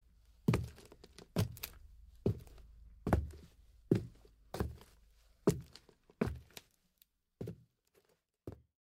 Footsteps On Wood